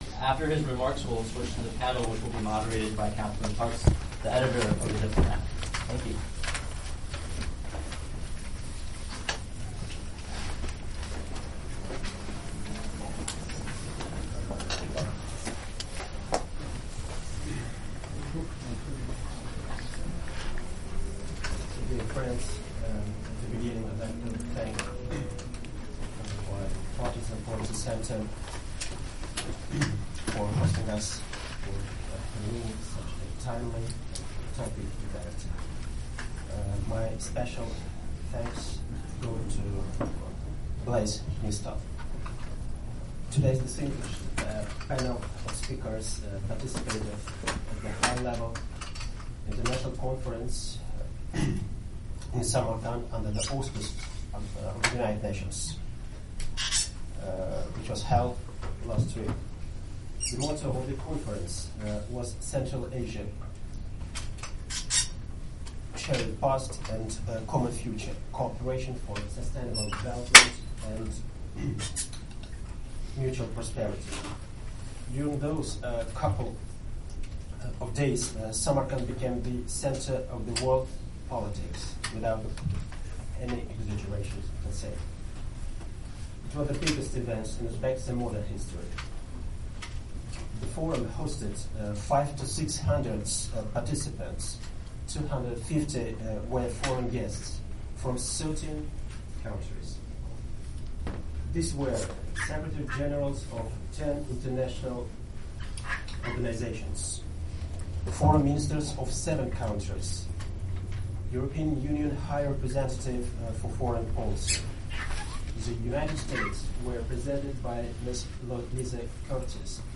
Uzbekistan discussion at Bipartisan Policy Center, Nov 21, 2017